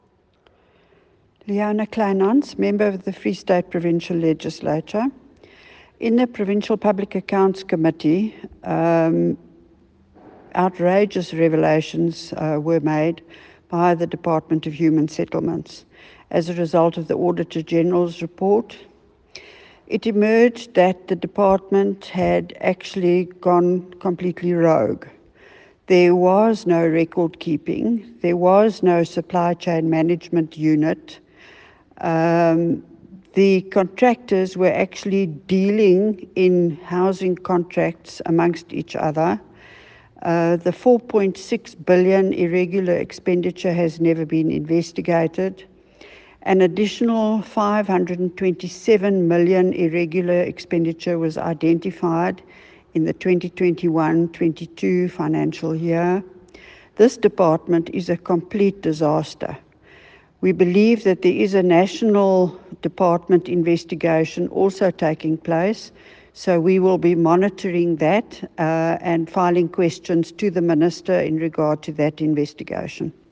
Afrikaans soundbites by Leona Kleynhans MPL